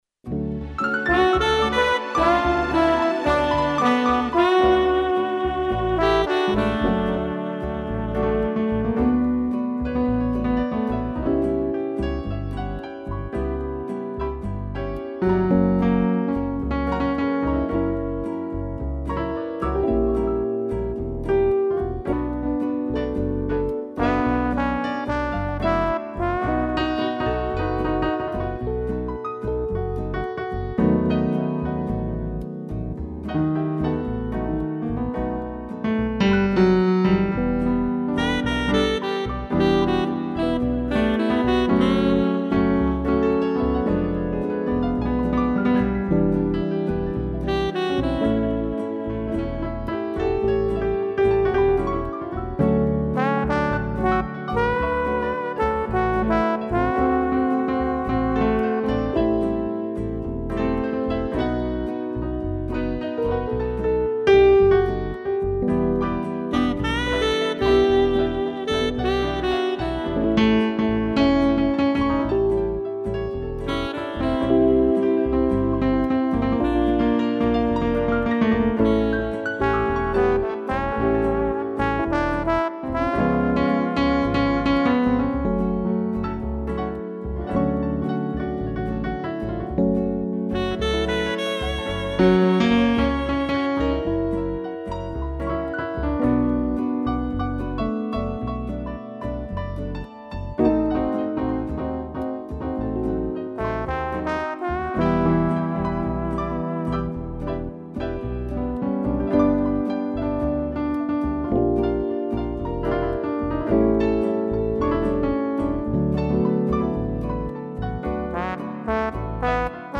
piano, trombone e sax
(instrumental)